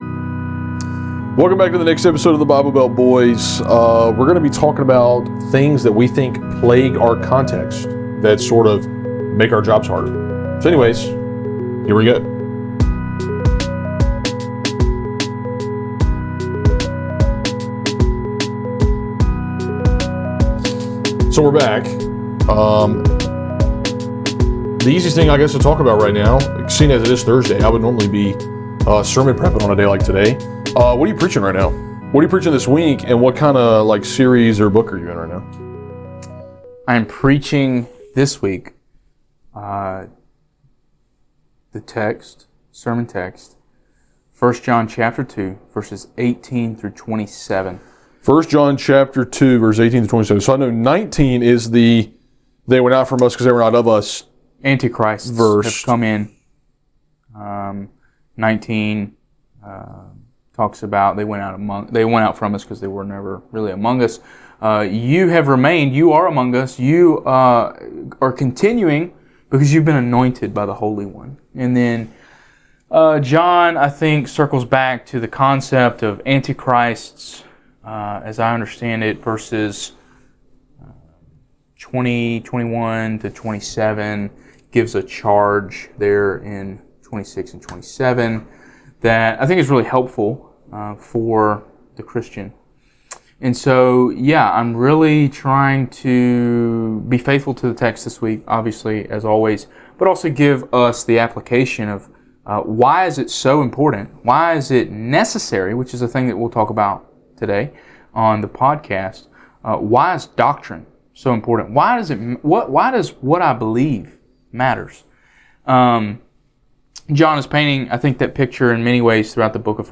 On this two-part podcast conversation, the Bible Belt Boys drill down on some of the unique challenges that plague the Bible Belt churches many of you may attend.